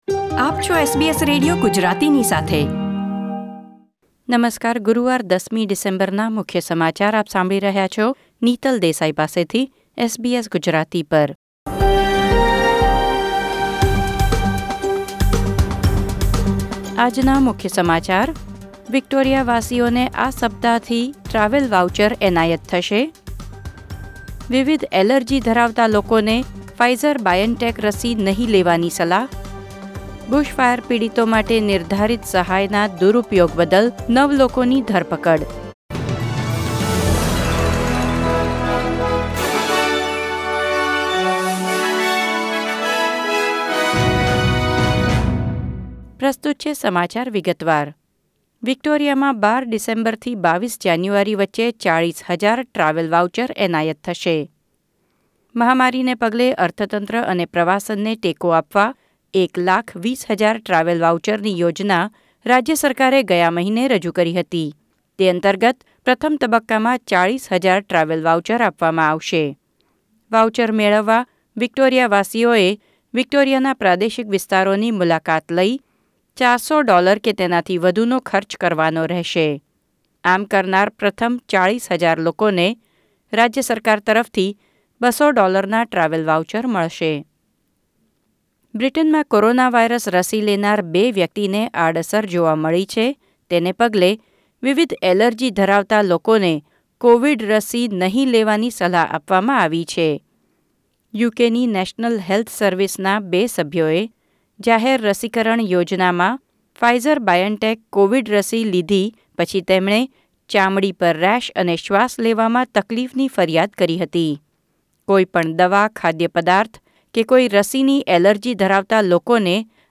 SBS Gujarati News Bulletin 10 December 2020